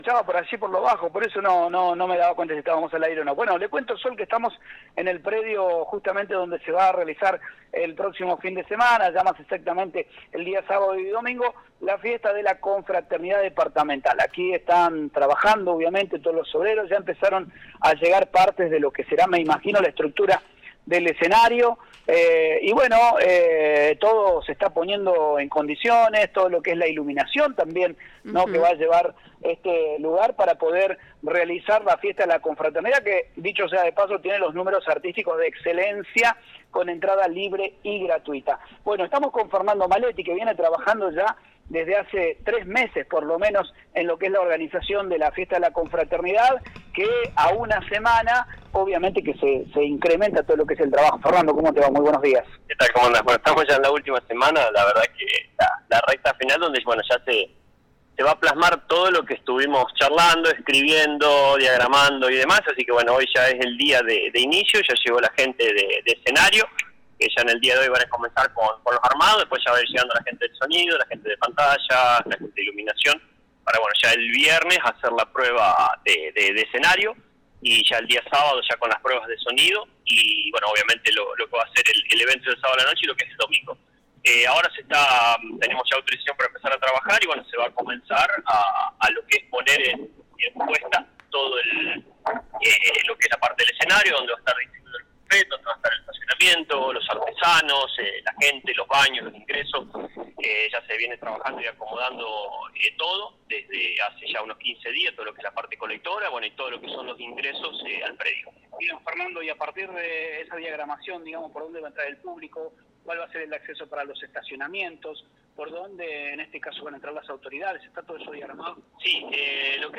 Desde el predio del Club de Planeadores, en un móvil en Vivo que realizó FM CERES 98.7 Mhz. el Subsecretario de Cultura, Deportes y Educación, dio todos los detalles de la organización de la Fiesta de la Confraternidad Departamental.